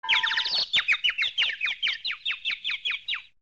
Звуки мультяшных ударов
Звук птичек после удара по голове в мультфильмах